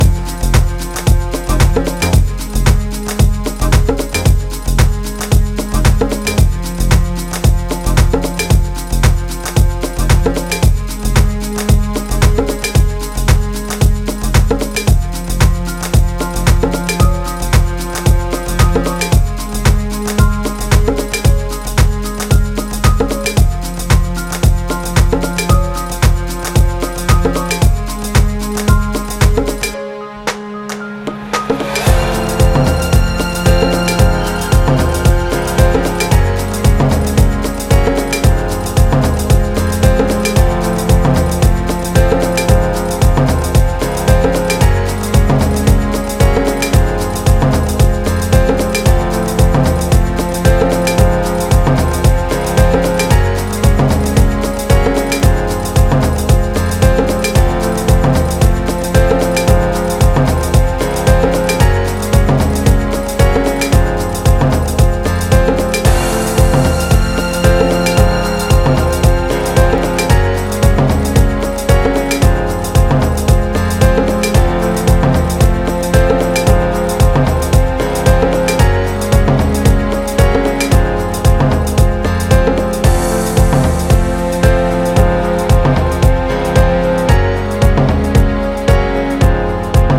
ミドルテンポで様子を伺いつつピアノやストリングスを交え壮大にビルドアップする